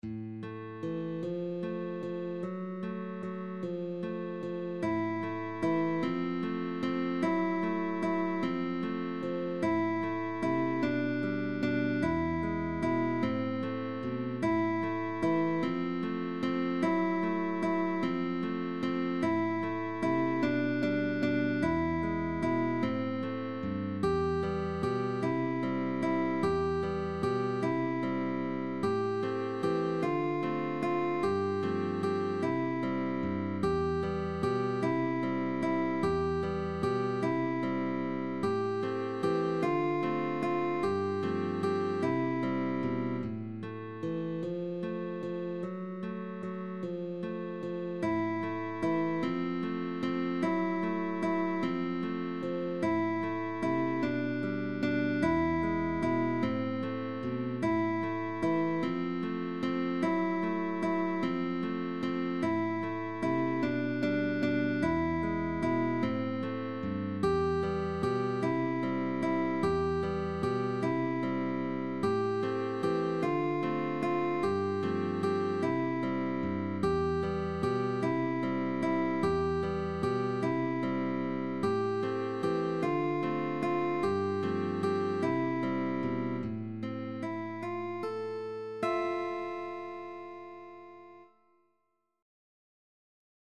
MELODIC GUITARGUITAR DUO: PUPIL and TEACHER Right hand: Index and middle, Rest Stroke.Left Hand: First Position.Natural Notes.